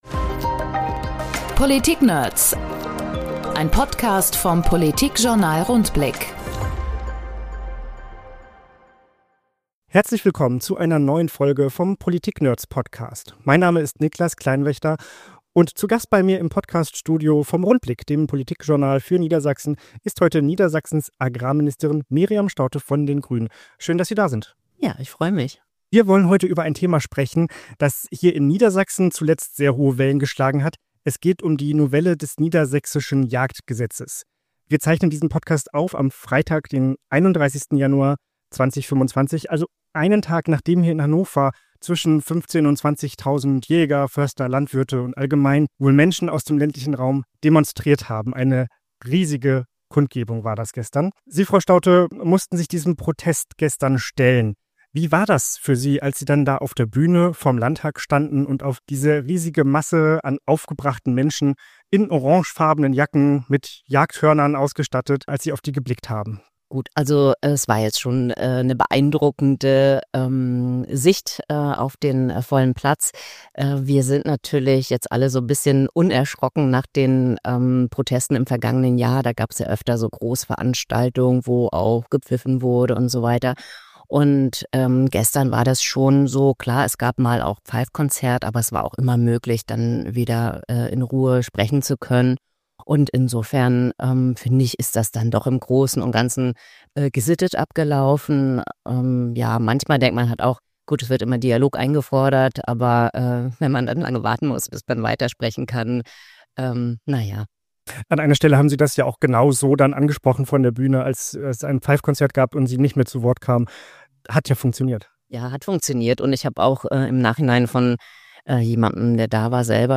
In unserem Podcast sprechen die Redakteure des Politikjournals Rundblick mit anderen Politikverrückten: Abgeordneten, Ministerinnen, Interessenvertretern und vielen mehr.